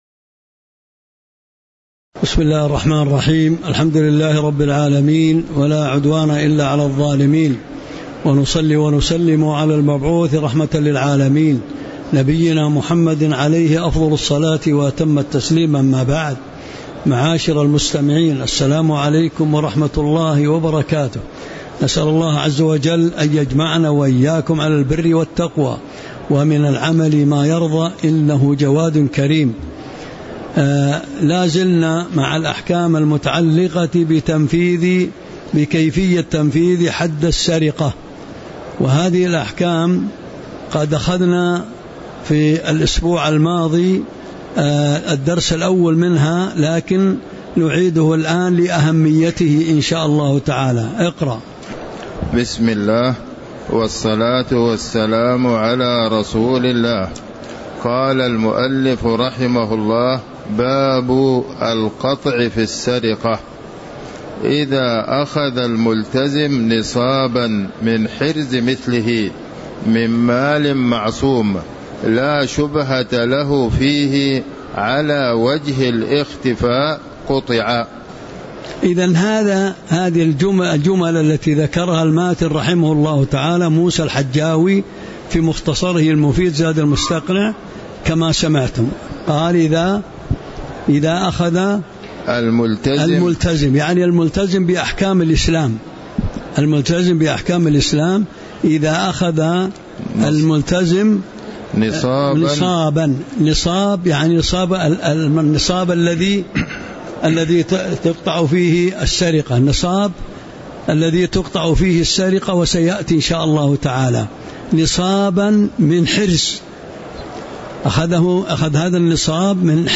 تاريخ النشر ٢٤ صفر ١٤٤٥ هـ المكان: المسجد النبوي الشيخ